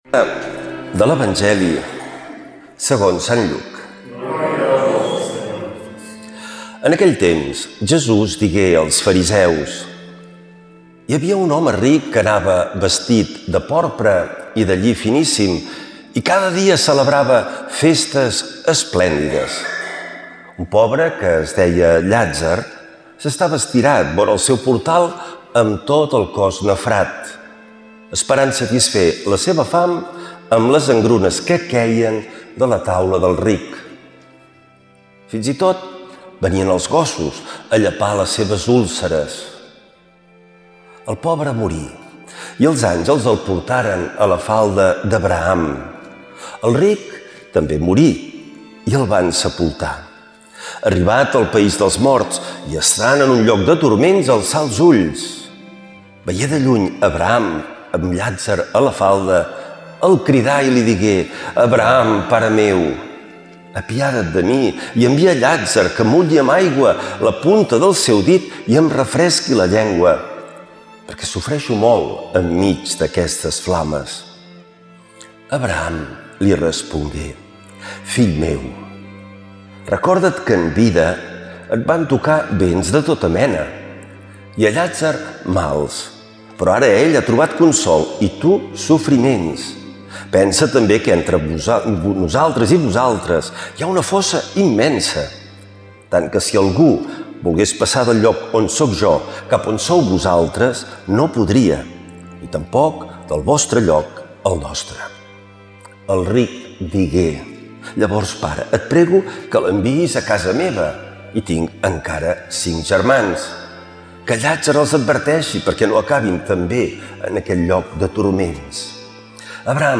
Lectura de l’evangeli segons sant Lluc